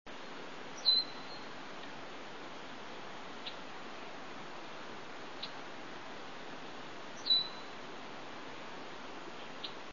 Red-winged Blackbird
Great Swamp, 3/18/00, "tseert" sound with "check" or "chit" in between.  Male, present near large flock of 200 blackbirds near swamp feeder (34kb)
blackbirdtseert301.wav